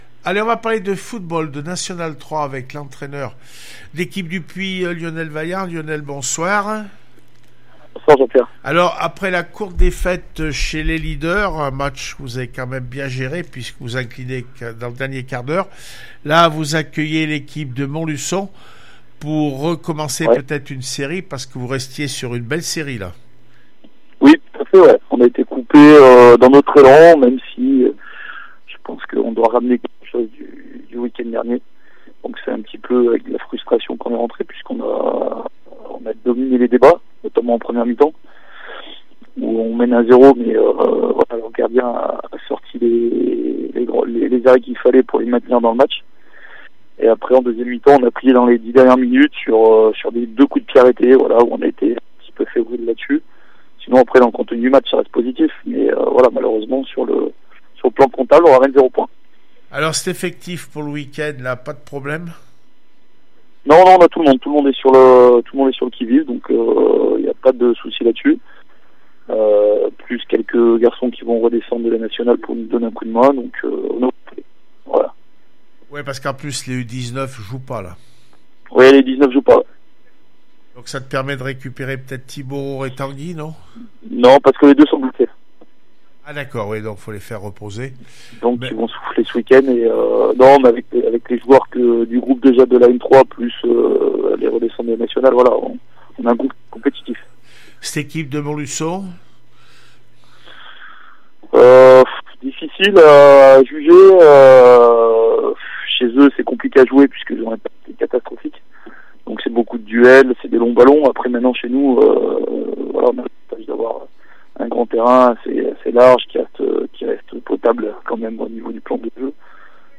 1 février 2020   1 - Sport, 1 - Vos interviews, 2 - Infos en Bref   No comments